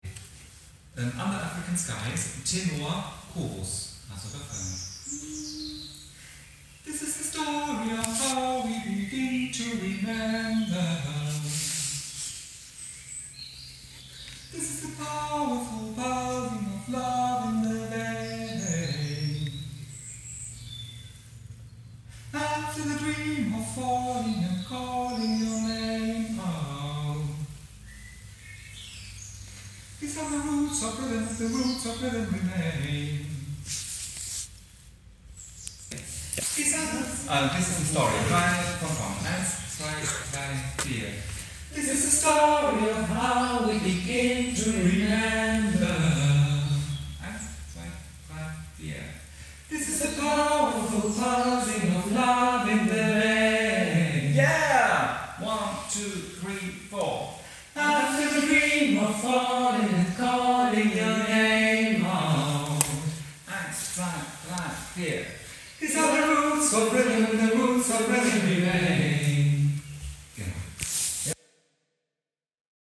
Tenor – Chorus
Tenor-Chorus-UnderAfricanSkys.mp3